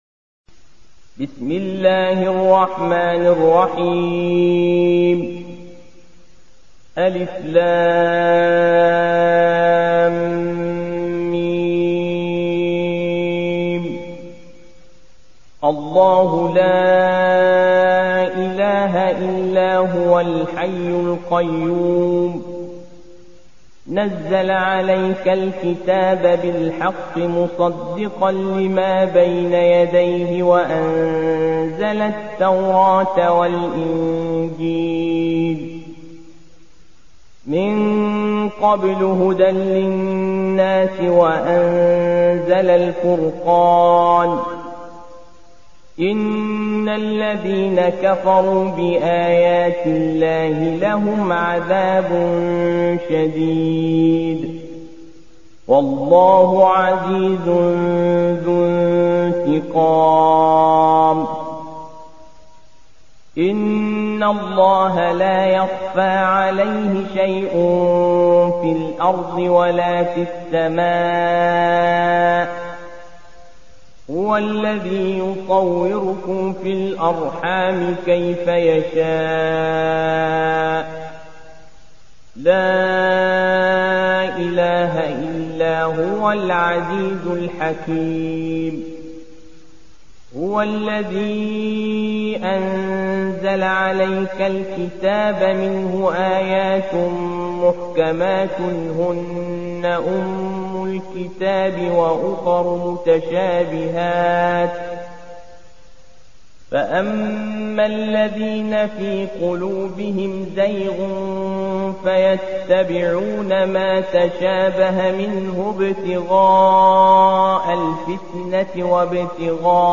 روایت حفص از عاصم